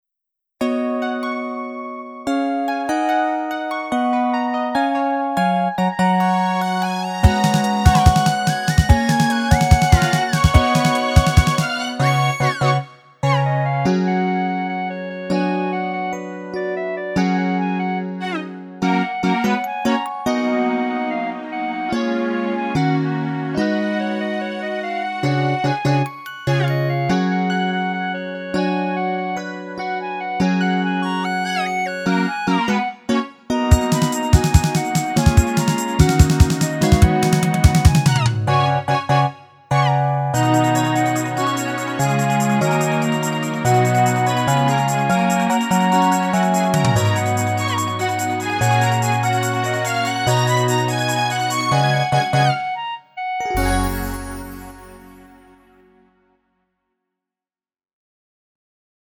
음정 -1키 0:59
장르 가요 구분 Lite MR
Lite MR은 저렴한 가격에 간단한 연습이나 취미용으로 활용할 수 있는 가벼운 반주입니다.